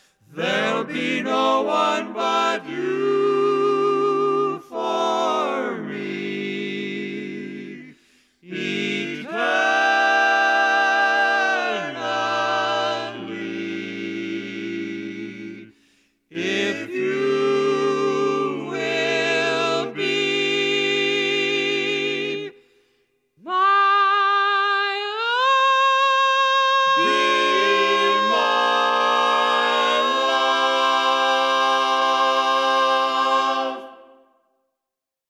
Key written in: C Major
How many parts: 4
Type: Barbershop
All Parts mix: